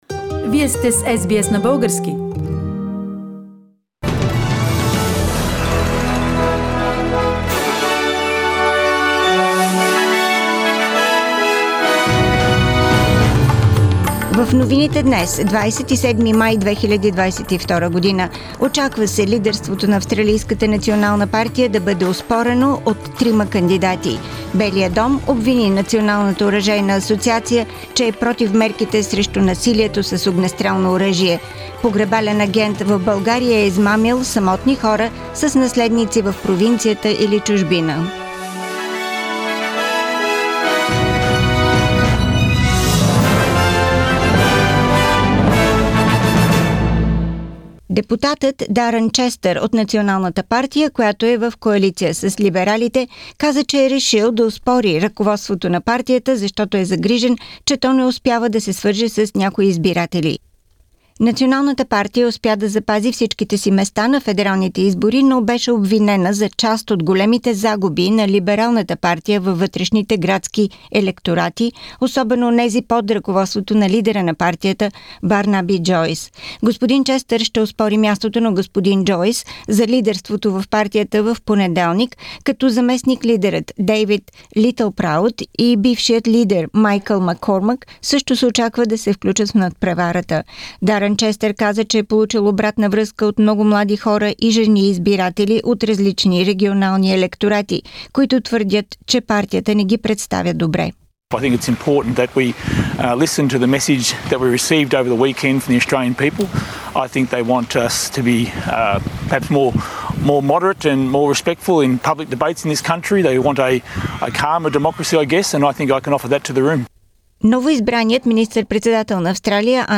Weekly Bulgarian News – 27th May 2022
Седмичен преглед на новините.